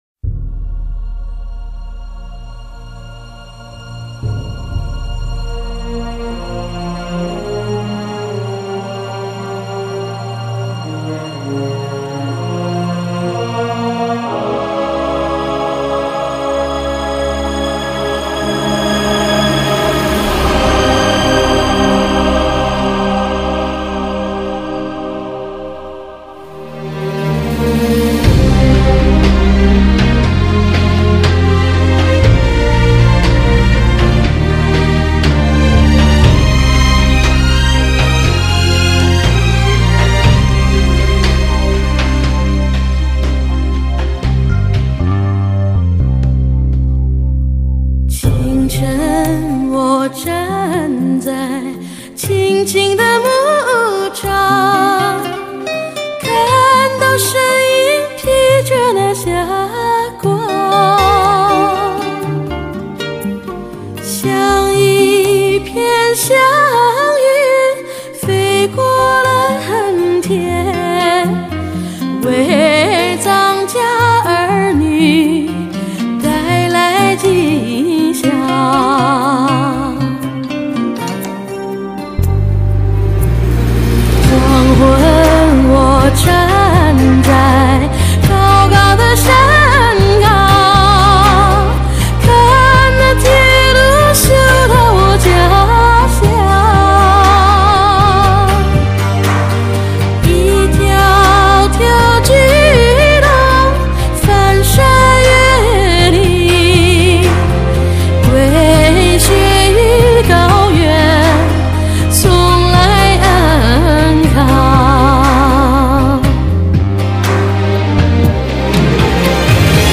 华语乐坛传奇女声